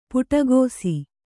♪ puṭagōsi